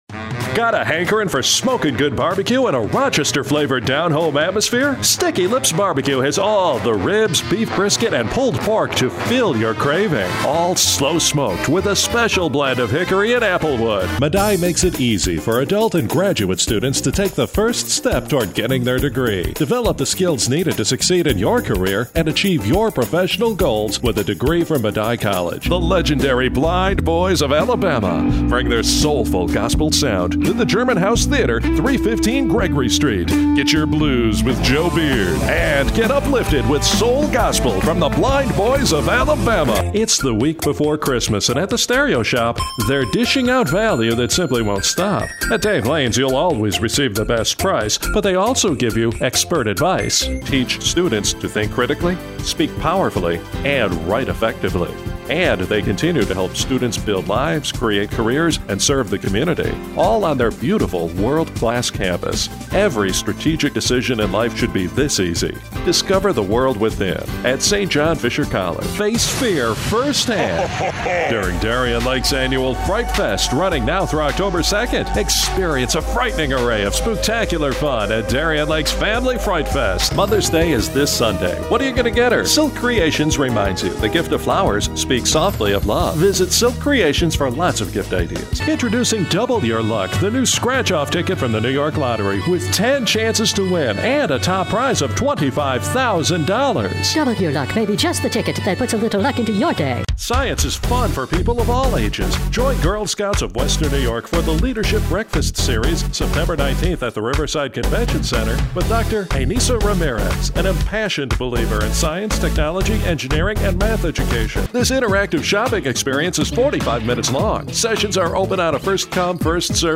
Commercial VO and Production Demo